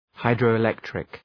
Shkrimi fonetik{,haıdrəʋı’lektrık}